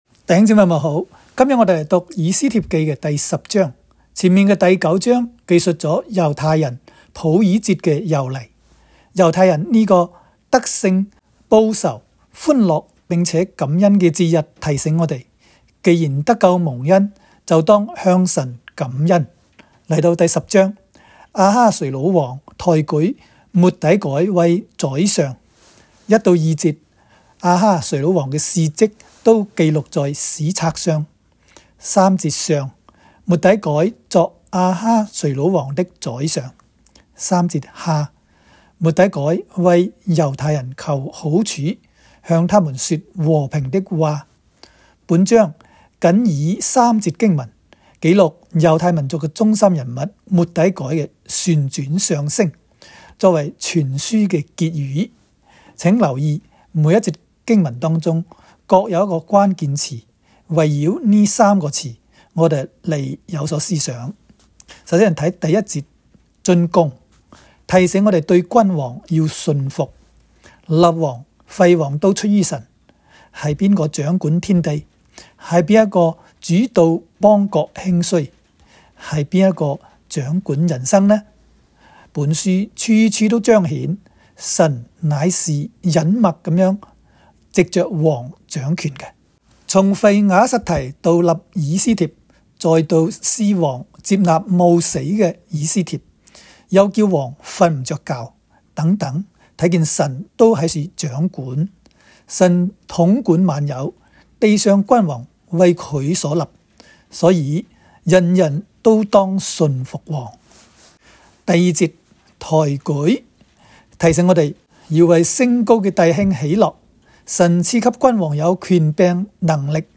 斯10（讲解-粤）.m4a